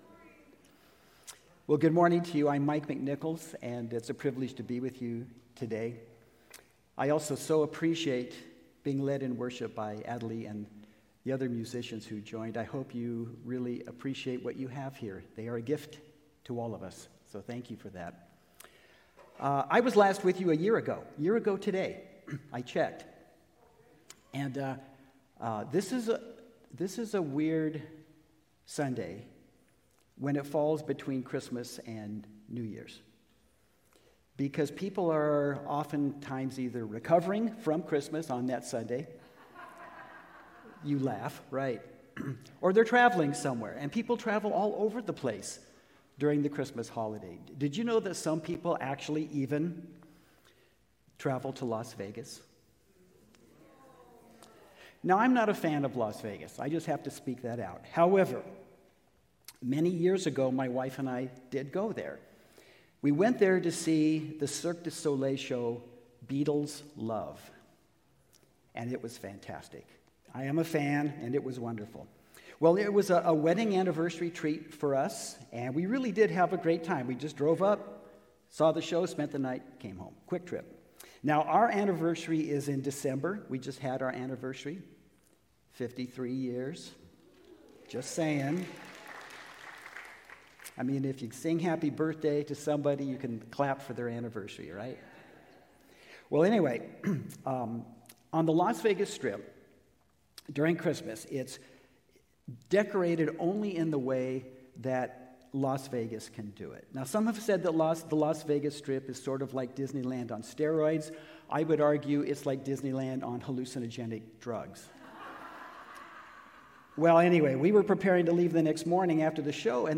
taft_avenue_community_church_sunday_worship_service_v2-540p.m4a